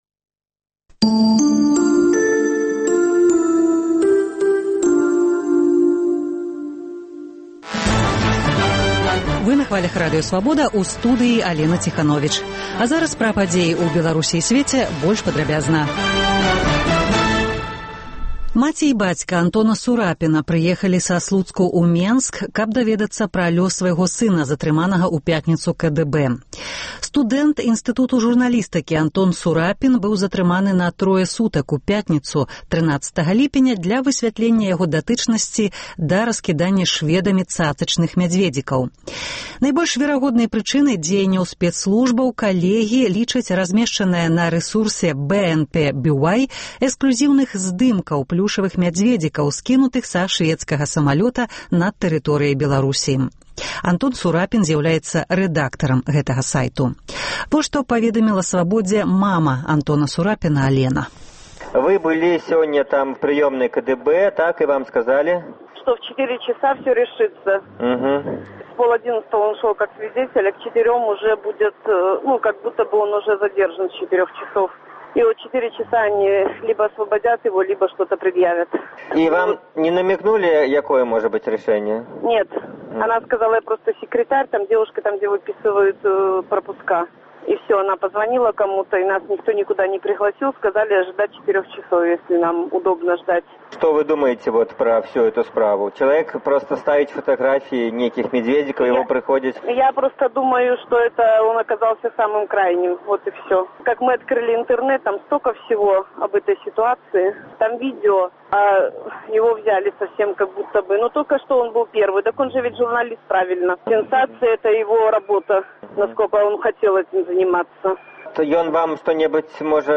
Вечаровы госьць, сацыяльныя досьледы, галасы людзей